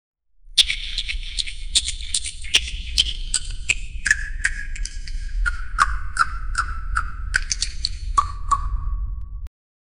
The sound of gnashing of teeth in Hell
the-sound-of-gnashing-of-pn3slib5.wav